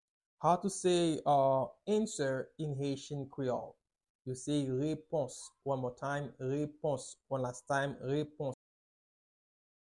Listen to and watch “Repons” audio pronunciation in Haitian Creole by a native Haitian  in the video below:
2.How-to-say-Answer-in-Haitian-Creole-–-Repons-pronunciation.mp3